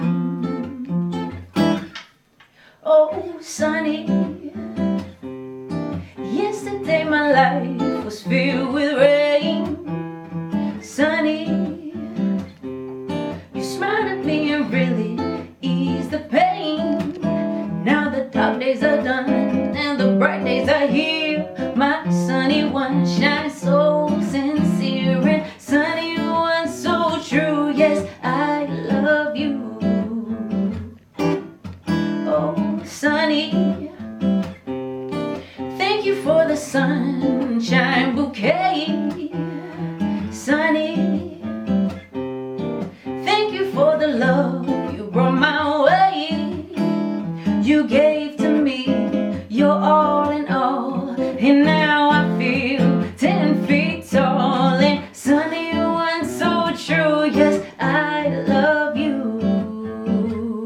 Soul, Rock und Pop
LIVE ACOUSTIC COVER